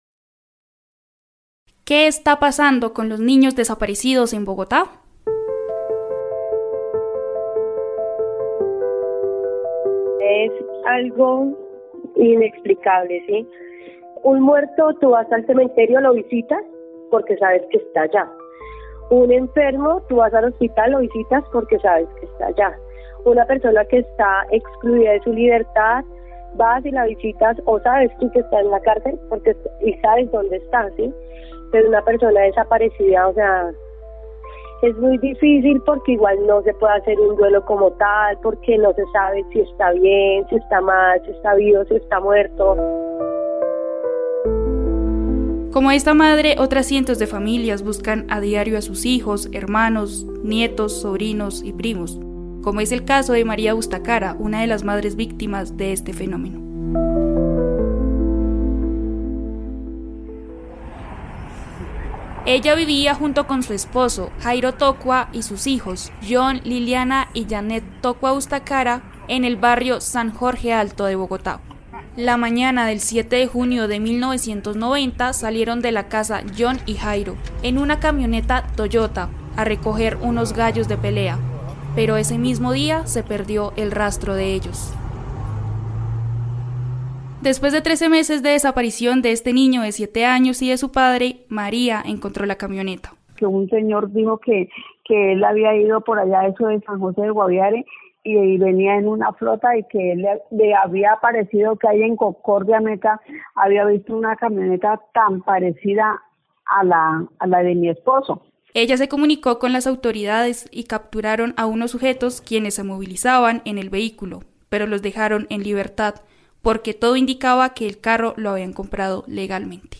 2024 Nos interesamos en la desaparición de niños porque creemos que es un flagelo que no recibe la atención que merece y que, tristemente, sigue ocurriendo en nuestra ciudad, por eso en esta crónica radial analizamos el contexto social, político y judicial que ha permitido que este problema crezca. https